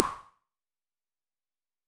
REDD PERC (21).wav